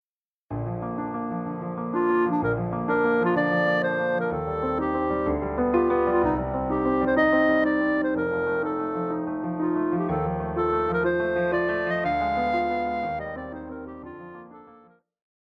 Classical
Vocal - female,Vocal - male
Piano
Etude
Voice with accompaniment